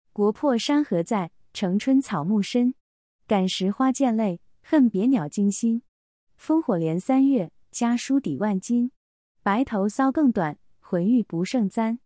それぞれに中国語の朗読音声も付けておりますの韻を含んだリズム感を聞くことができます。